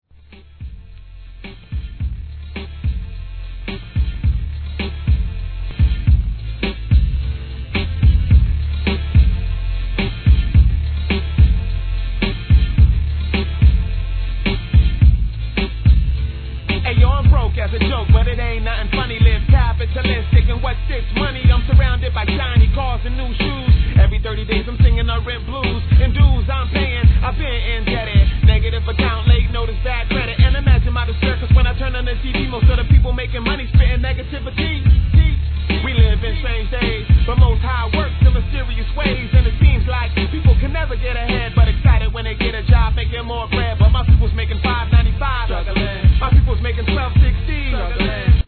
HIP HOP/R&B